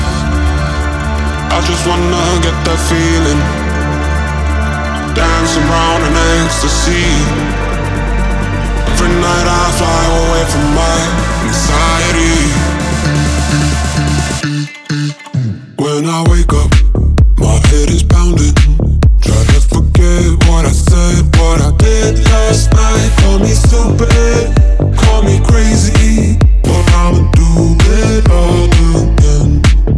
Жанр: Танцевальная музыка